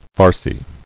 [far·cy]